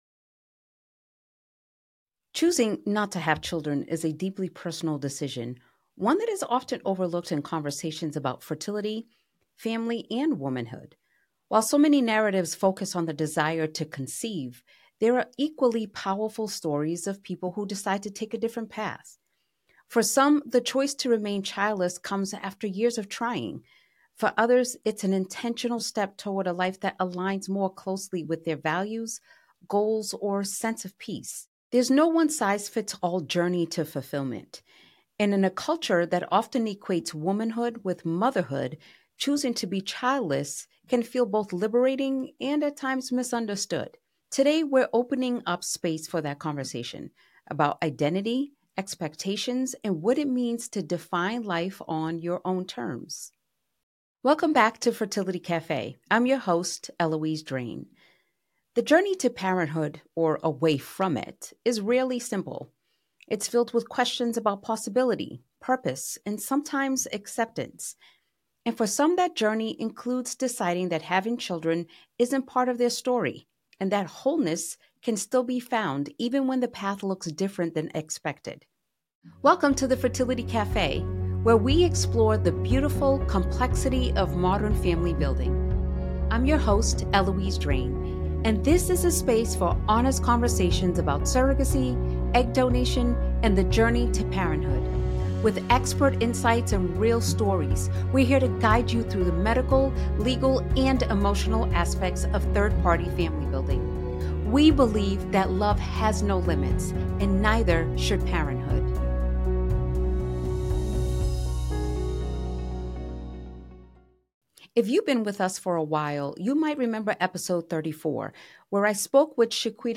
It is a thoughtful, often emotional, and deeply grounded conversation about identity, legacy, caregiving, and the path not taken.